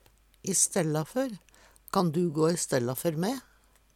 i stella før - Numedalsmål (en-US)